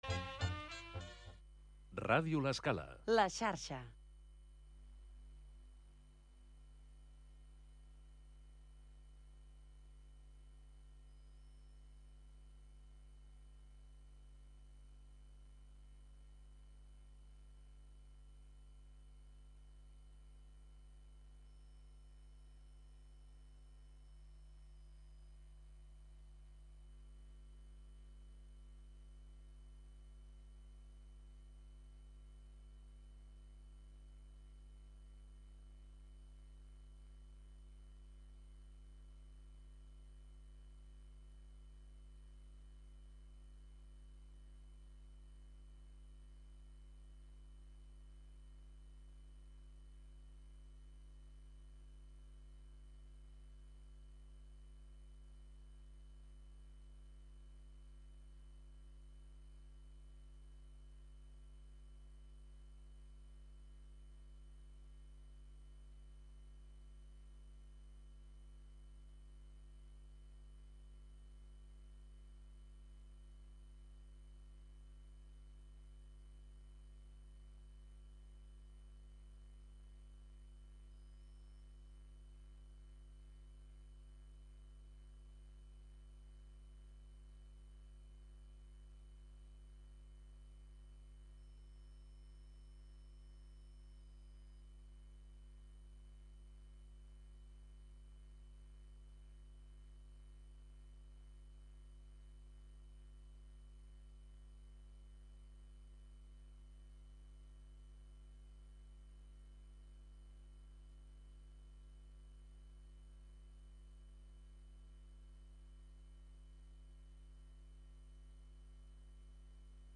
L'havanera i el cant de taverna en son els protagonistes